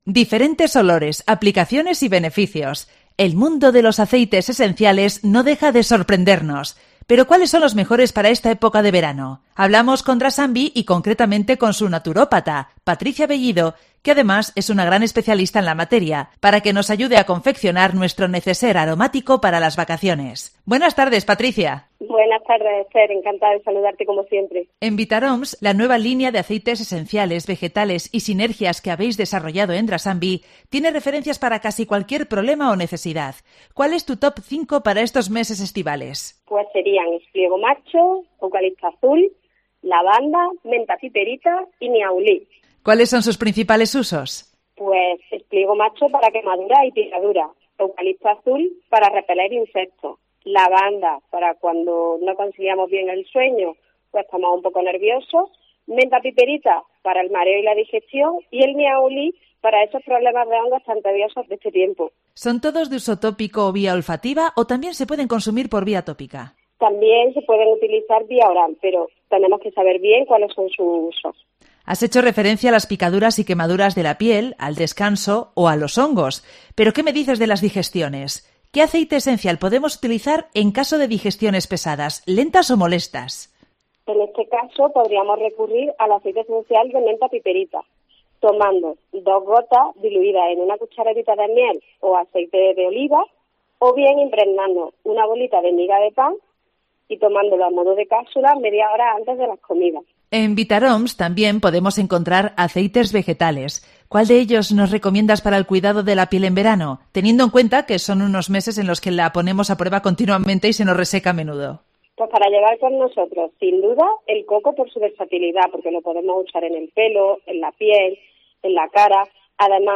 En Directo COPE LEÓN